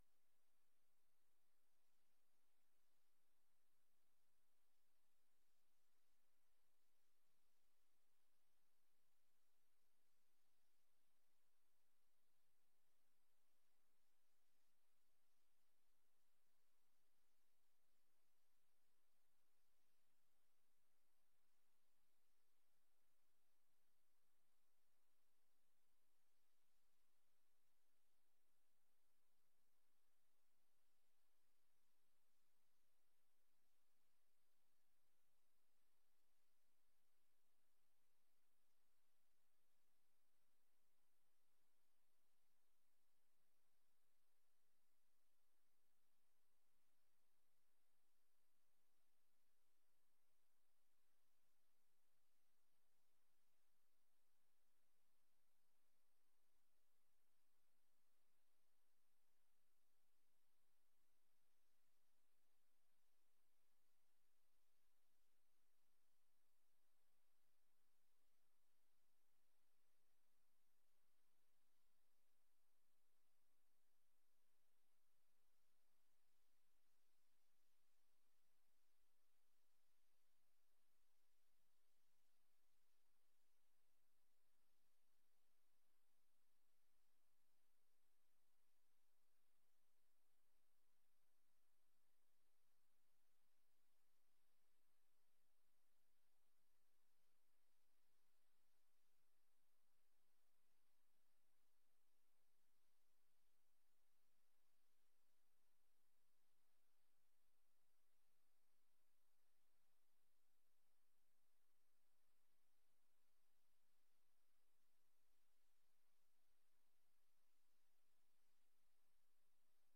Download de volledige audio van deze vergadering
Locatie: Raadzaal